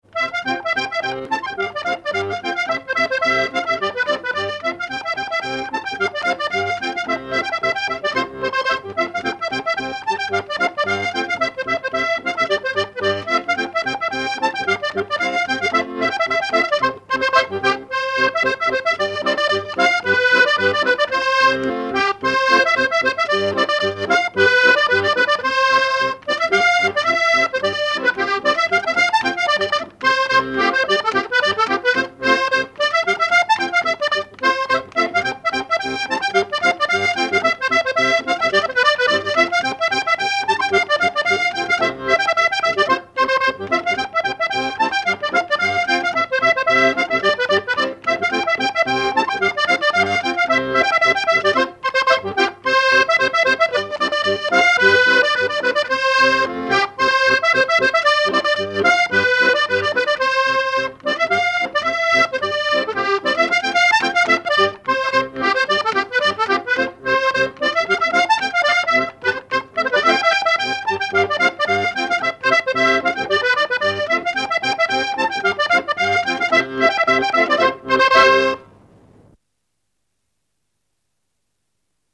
Enregistrement original de l'édition sur disque vinyle
musique pour les assauts de danse et le bal.
danse : pas d'été
Pièce musicale inédite